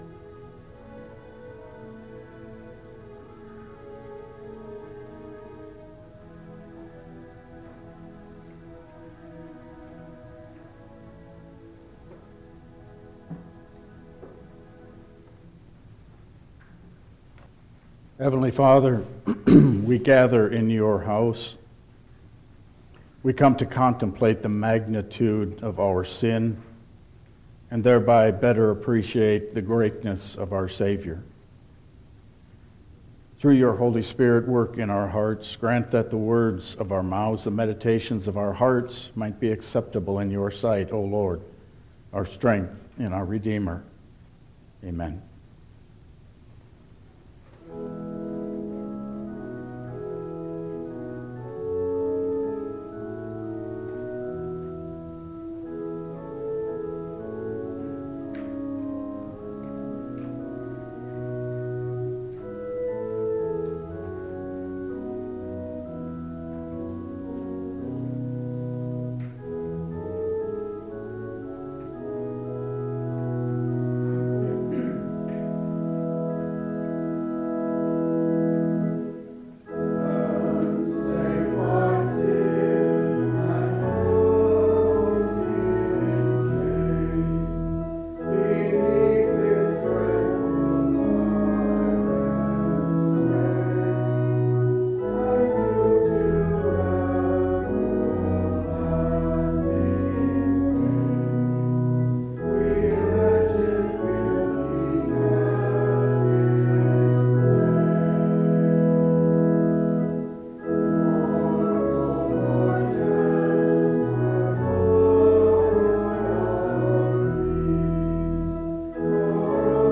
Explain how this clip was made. Service Type: Lenten Service